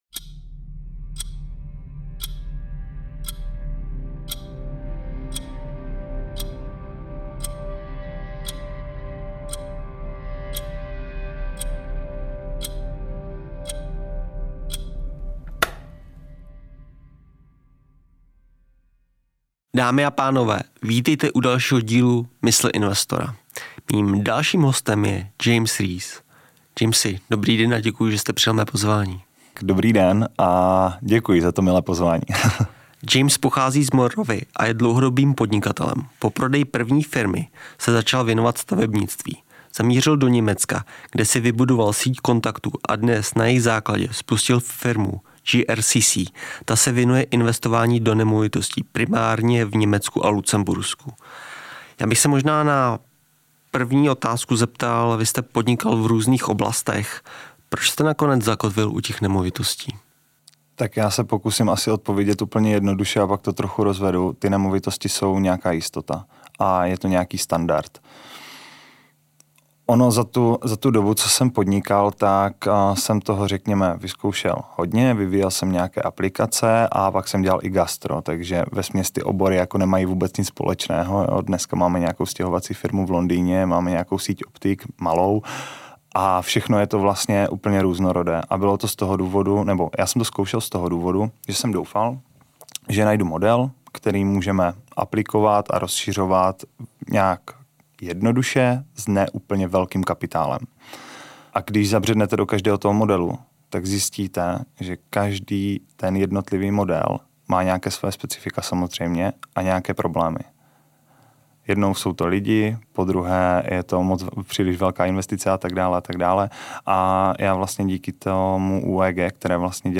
V dnešním díle si můžete poslechnout rozhovor